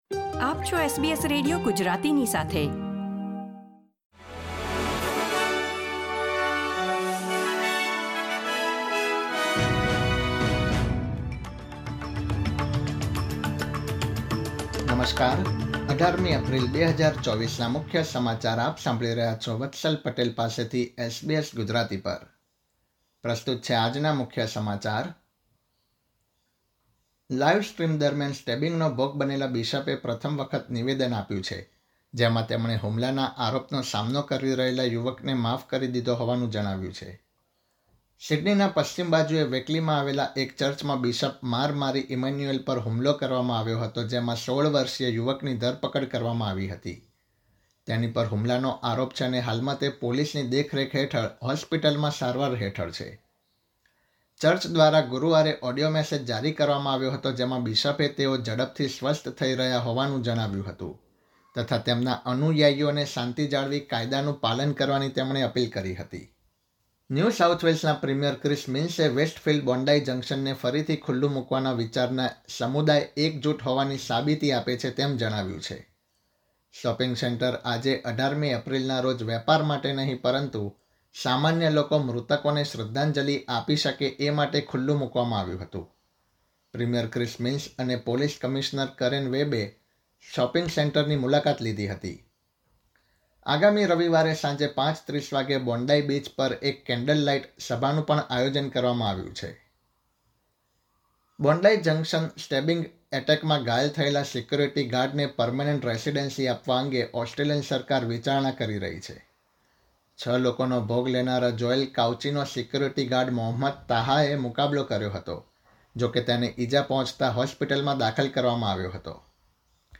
SBS Gujarati News Bulletin 18 April 2024